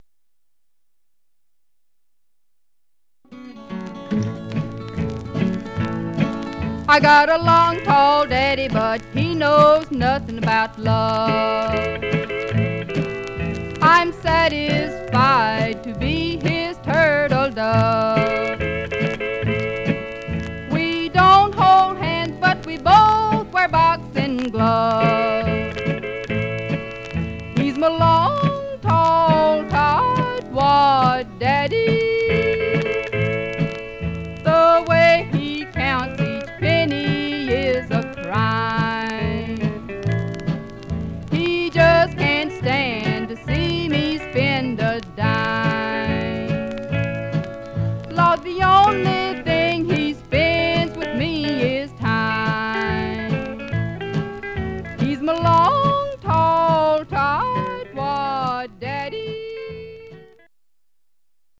Surface noise/wear
Mono
Country